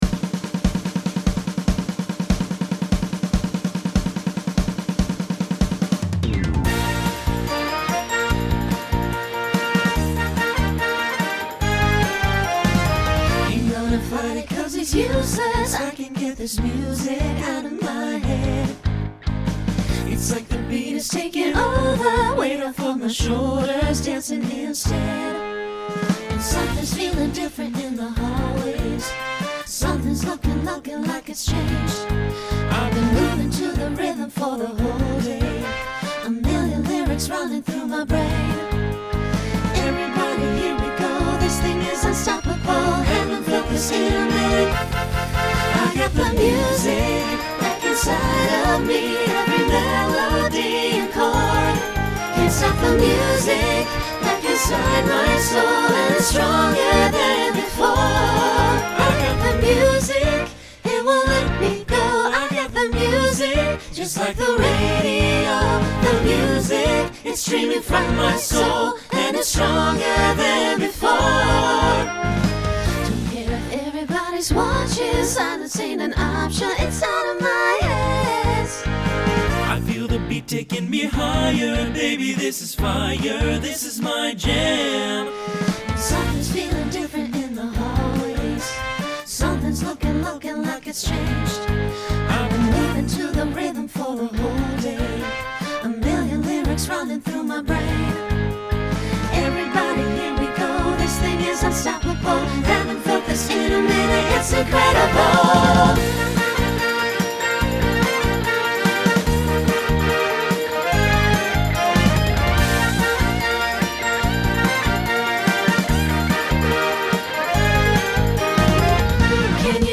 Includes custom bow.
Genre Broadway/Film , Pop/Dance Instrumental combo
Voicing SATB